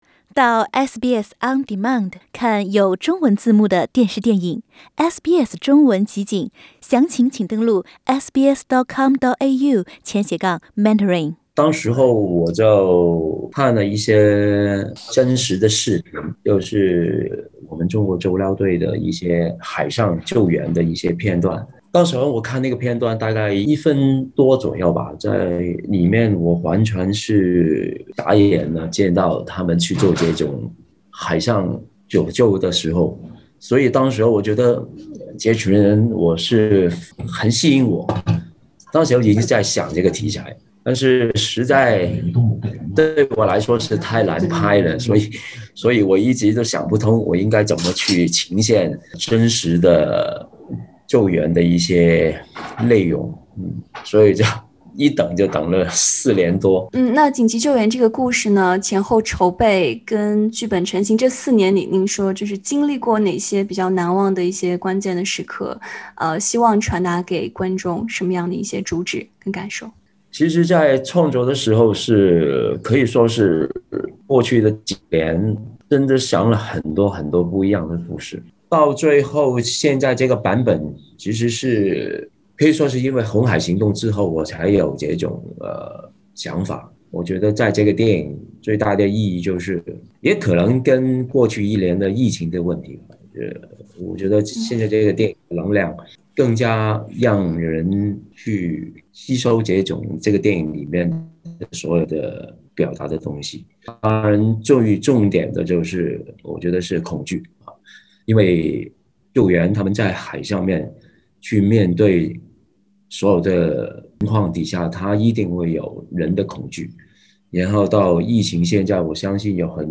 导演林超贤在接受SBS普通话节目采访时表示，希望这部电影能传达积极信号，让观众获得勇气并直面恐惧。